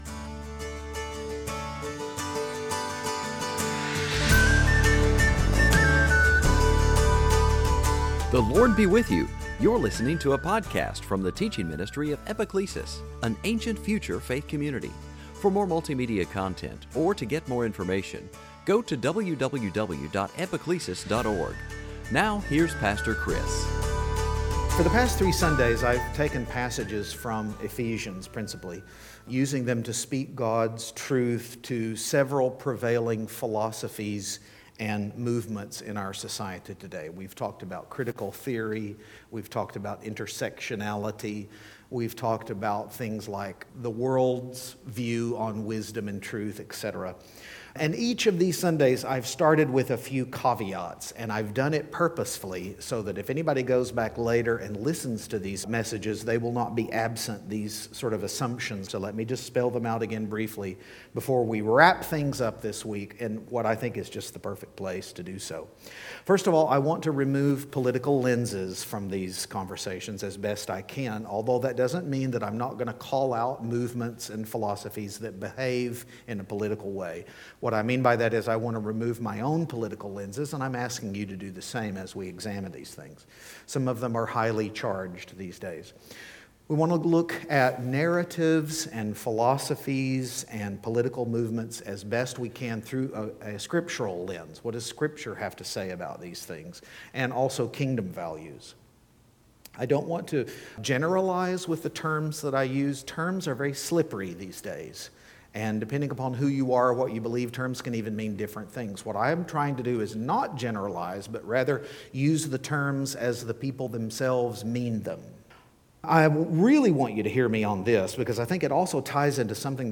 Sunday Teaching